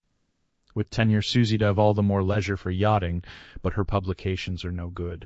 speech-style-transfer text-to-speech voice-cloning